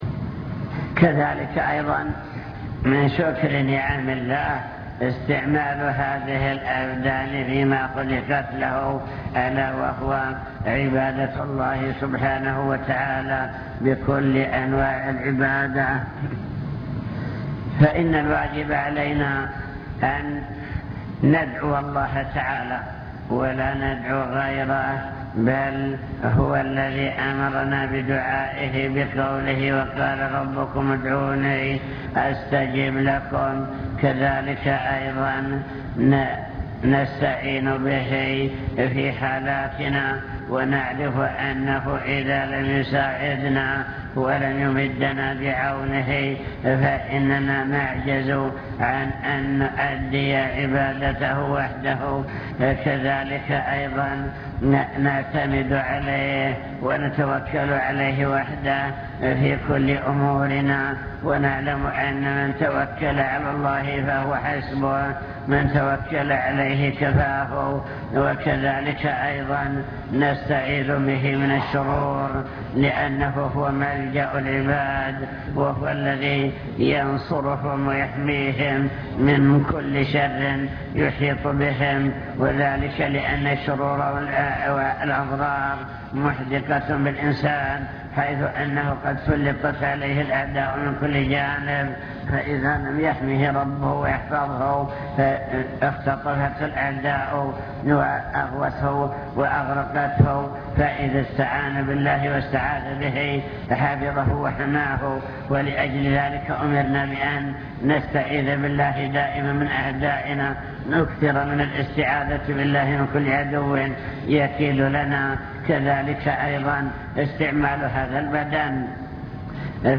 المكتبة الصوتية  تسجيلات - محاضرات ودروس  محاضرة بعنوان شكر النعم (2) واجب الإنسان نحو النعم